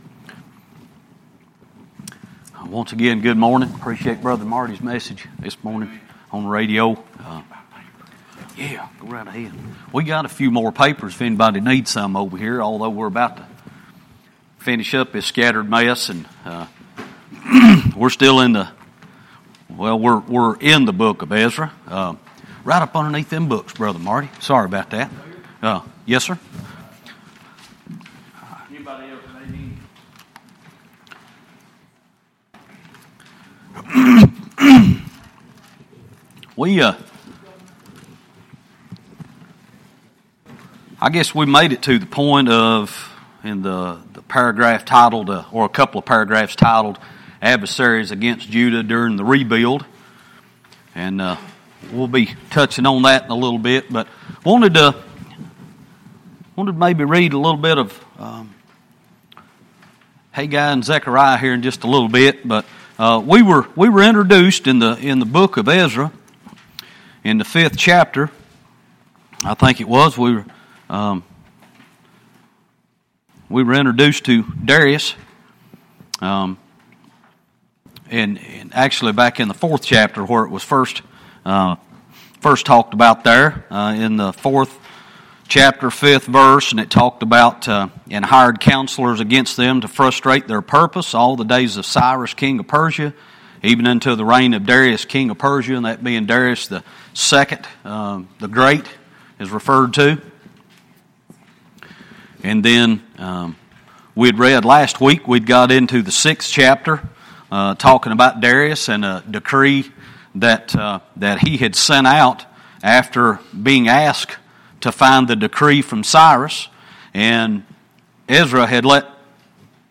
Sunday-School-6-14-20.mp3